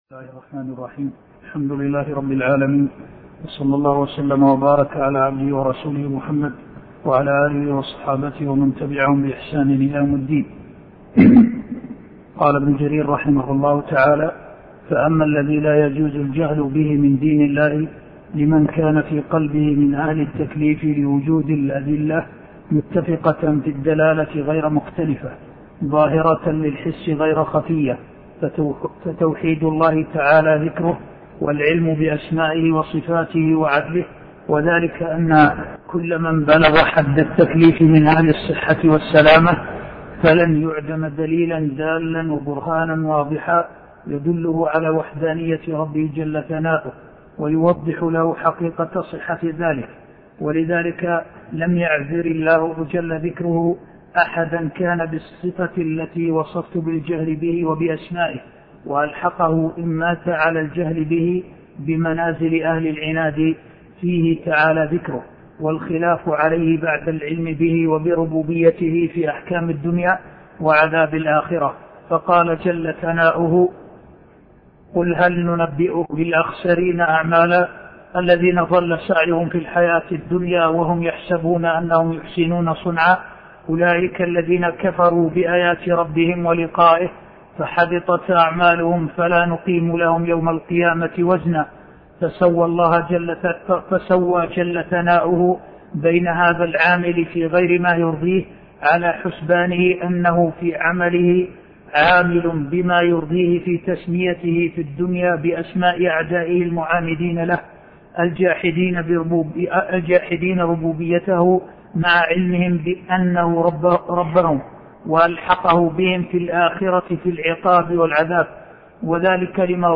عنوان المادة الدرس (4)شرح كتاب التبصير في معالم الدين لابن جرير الطبري تاريخ التحميل السبت 31 ديسمبر 2022 مـ حجم المادة 42.82 ميجا بايت عدد الزيارات 263 زيارة عدد مرات الحفظ 93 مرة إستماع المادة حفظ المادة اضف تعليقك أرسل لصديق